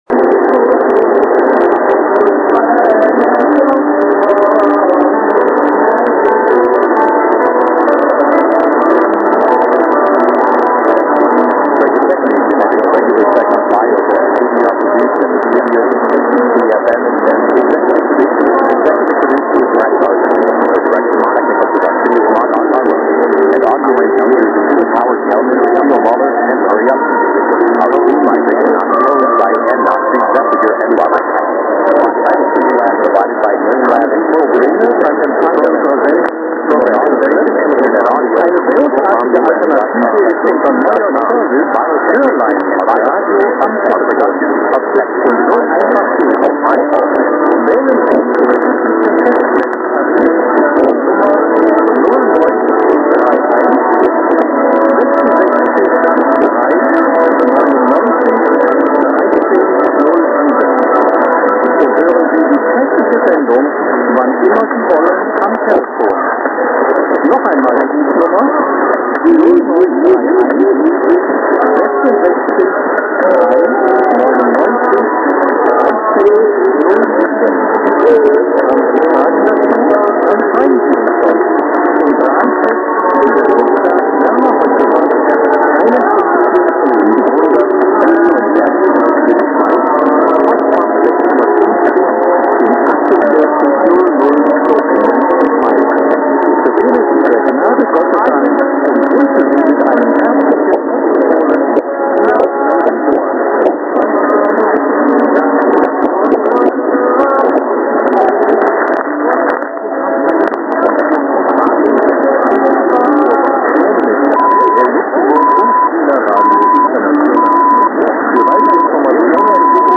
・このＨＰに載ってい音声(ＩＳとＩＤ等)は、当家(POST No. 488-xxxx)愛知県尾張旭市)で受信した物です。
Mix　私にはＩＤが取れなかった。